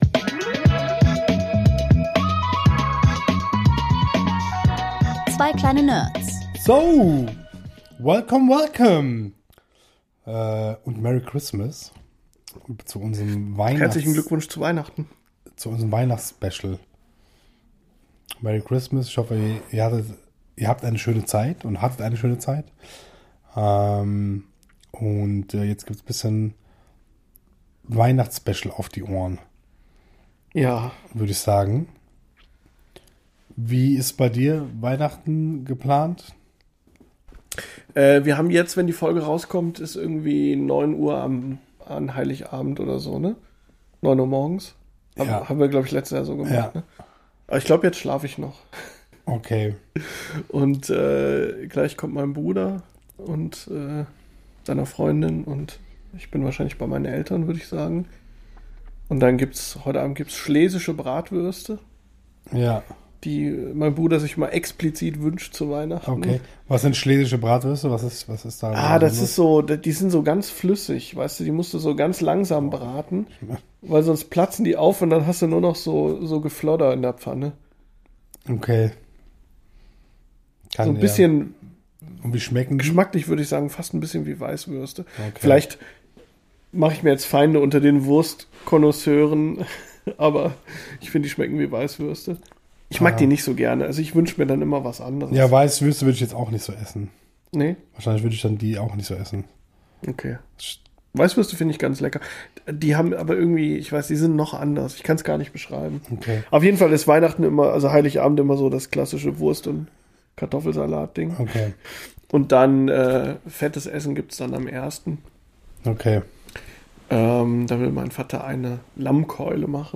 In der Folge: Märchen • Fun Facts • Rätsel/Quiz • viel Gelächter Mehr